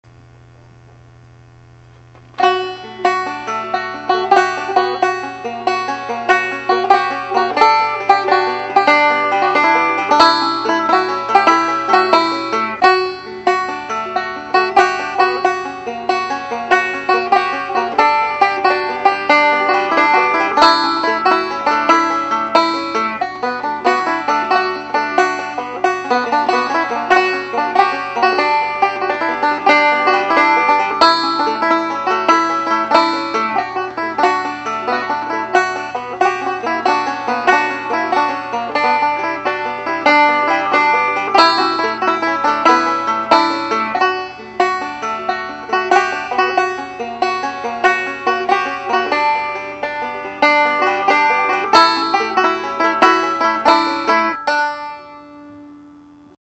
Quality: Good
An all-banjo ballad. For when you want to feel pretty.